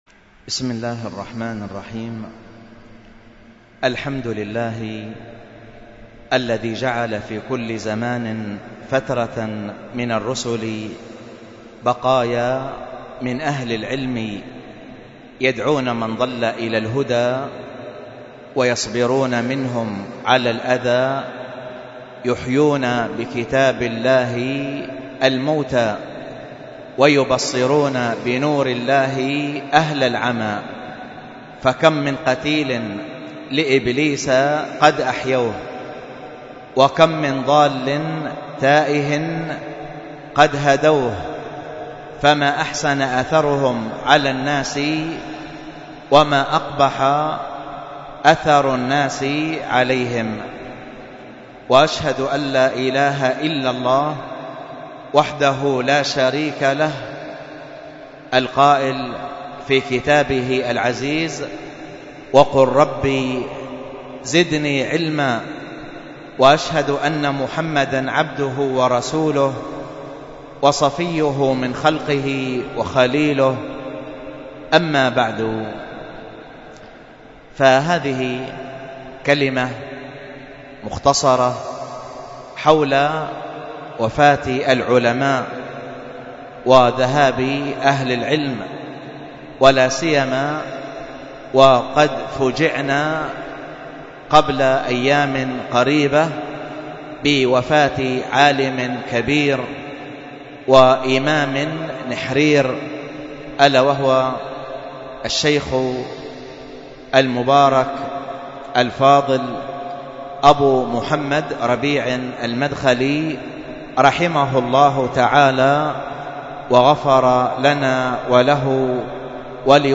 المحاضرة بعنوان صفحات من حياة العالم السلفي ربيع المدخلي - رحمه الله تعالى -، والتي كانت بمسجد السنة بدار الحديث بطيبة بالشحر